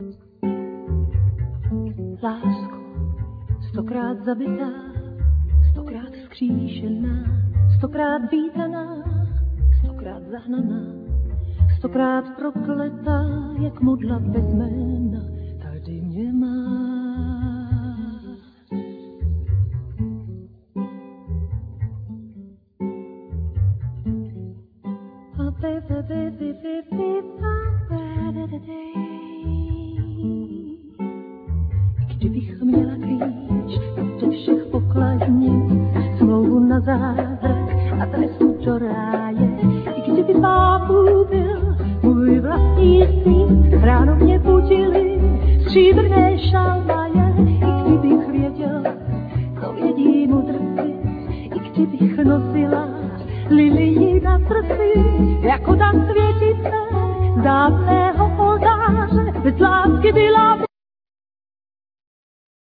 Vocal
El.+Ac.steel guitar
El.+Ac.nylon string guitar
Double-bass
Percussions
Piano